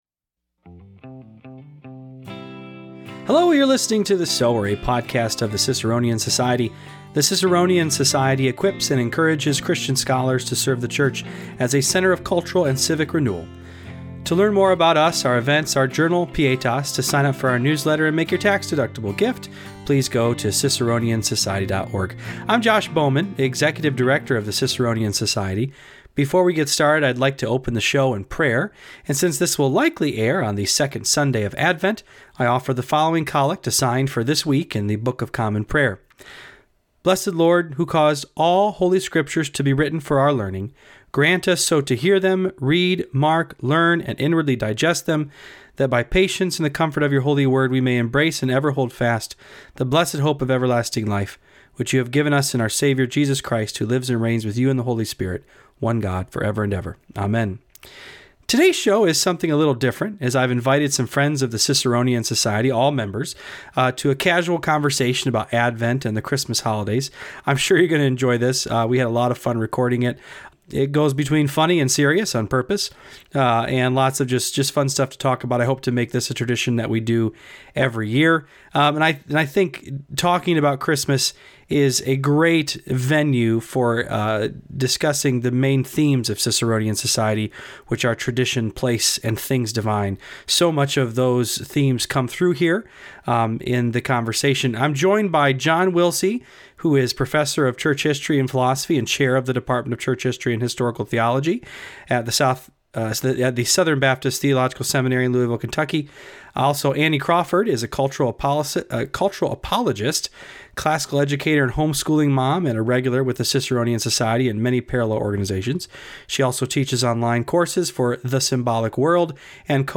EPISODE 62 - Christmas Traditions Roundtable